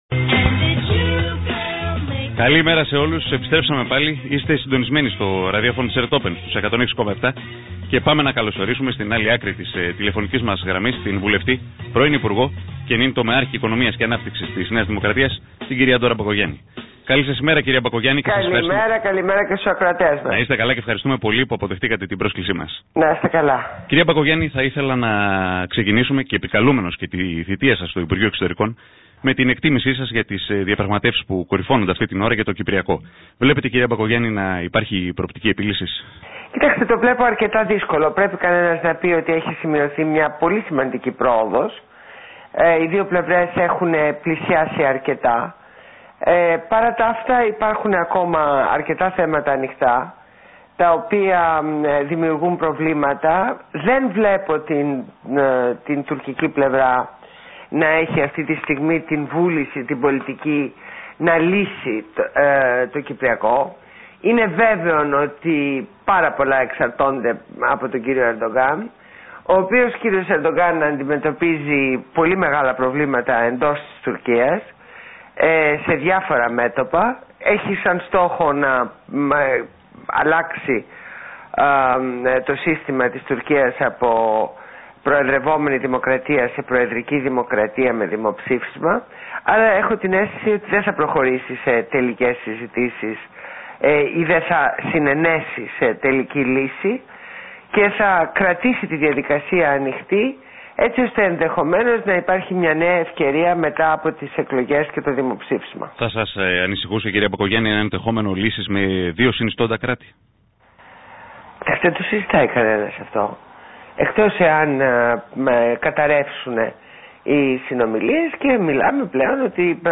Συνέντευξη στο ραδιόφωνο ΕΡΤ open